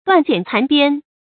斷簡殘編 注音： ㄉㄨㄢˋ ㄐㄧㄢˇ ㄘㄢˊ ㄅㄧㄢ 讀音讀法： 意思解釋： 斷、殘：不完整的；簡：古代用以寫字的竹、木片；編：細長皮條；指用它串簡而成的書。